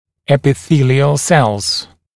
[ˌepɪ’θiːlɪəl selz][ˌэпи’си:лиэл сэлз]эпителиальные клетки, клетки эпителия